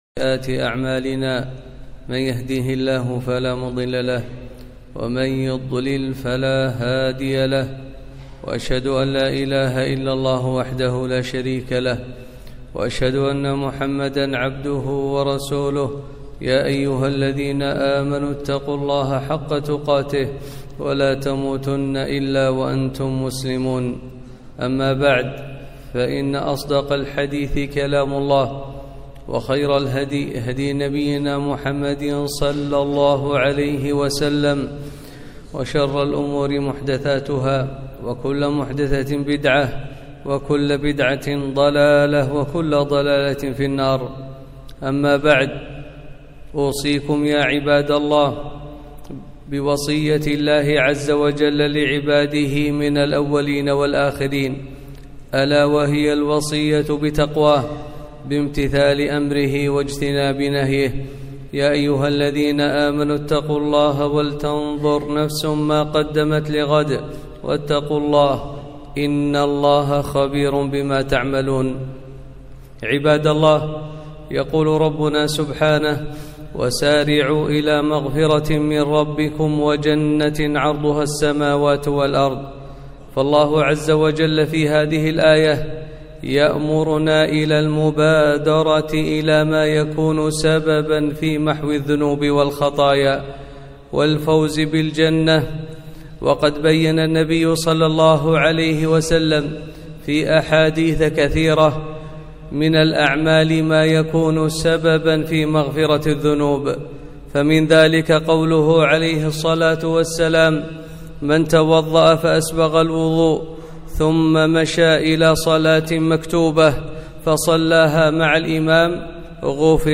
خطبة - ( وسارعوا إلى مغفرة من ربكم ) - دروس الكويت